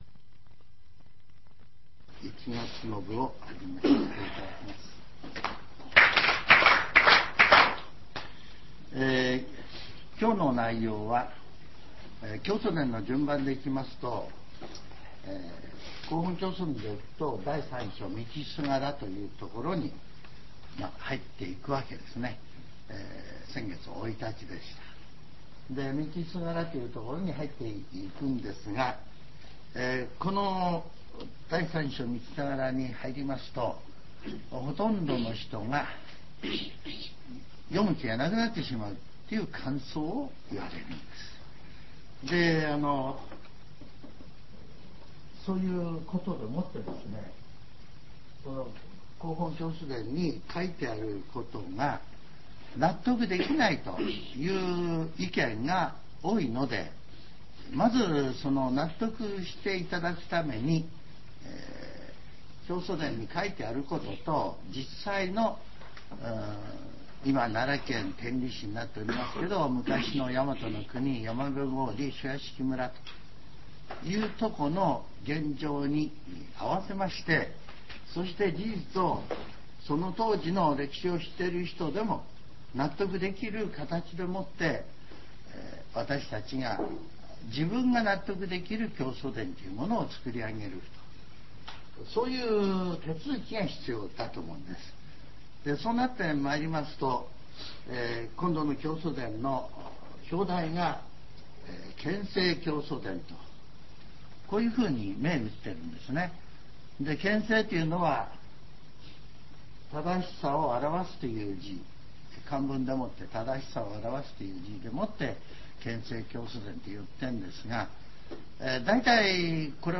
全70曲中17曲目 ジャンル: Speech